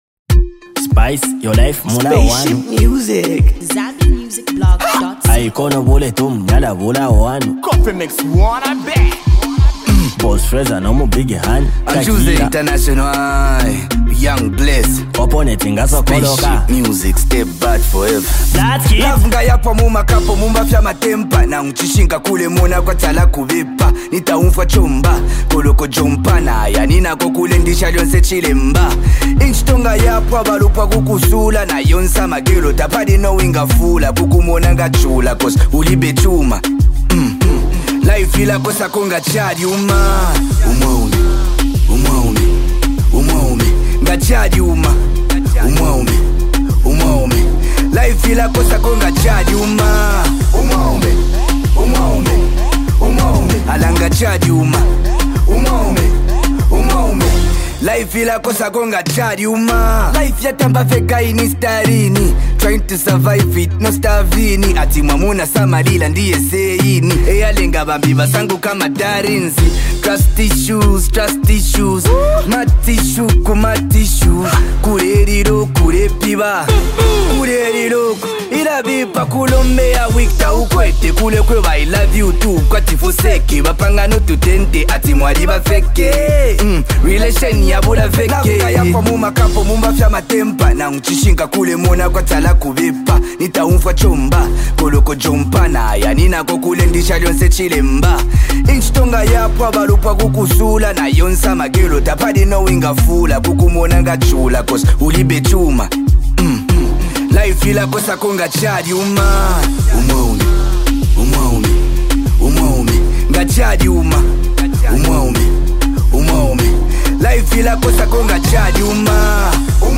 Copperbelt’s very own rap sensation